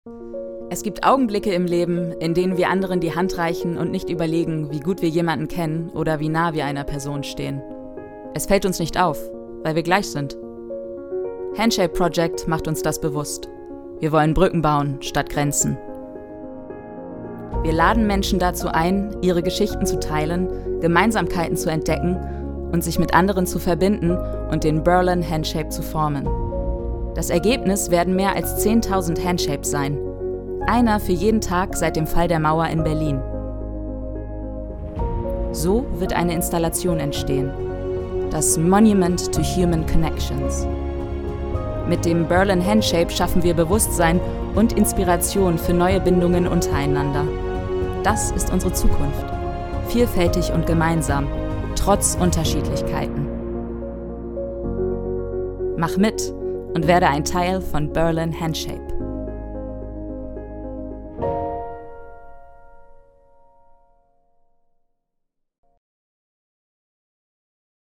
Narration
I specialize in bringing characters, commercials, and narrations to life with a warm, engaging, and versatile voice.
I work from my professional home studio, ensuring high-quality recordings, fast turnaround times, and a flexible, reliable service.
LowMezzo-Soprano